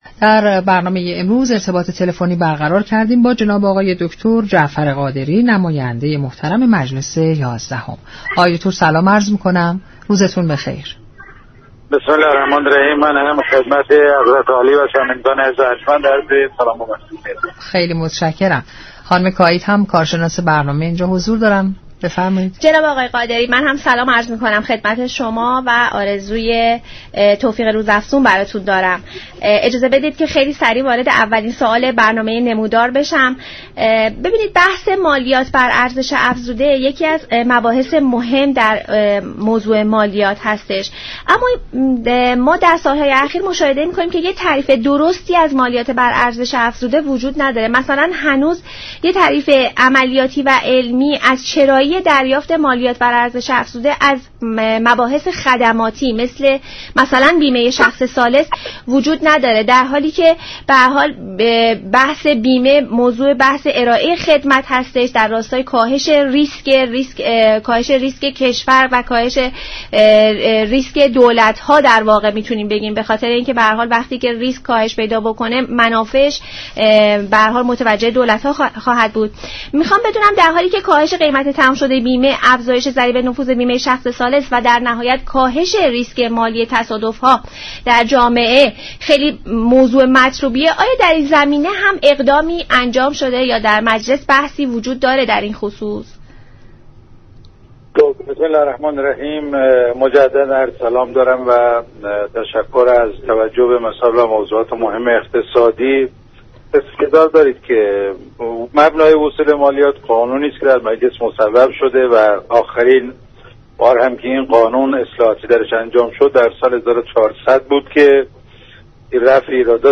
به گزارش شبكه رادیویی ایران، نماینده مردم شیراز و زرقان در مجلس شورای اسلامی و عضو كمیسیون برنامه،بودجه و محاسبات در برنامه نمودار به بحث مالیات بر ارزش‌‌افزوده پرداخت و گفت: در بحث وصول مالیاتی، مجلس آخرین اصلاحات را در سال 1400 انجام داده و قانون وصول مالیاتی از سه سال گذشته در ایران موجود است.